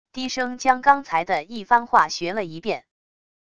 低声将刚才的一番话学了一遍wav音频生成系统WAV Audio Player